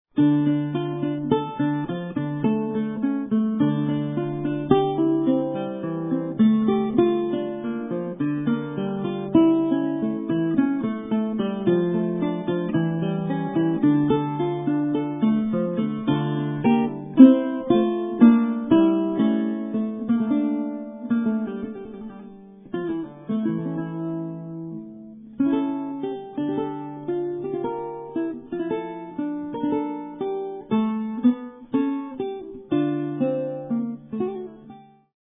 he plays the seldom heard Baroque guitar